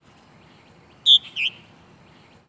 Purple Martin Progne subis
Gives a wide variety of other calls including a low, throaty "chrrr" and a rolling, descending "chee-chrr-chr".
Variety of calls from perched and flying birds near a nest house.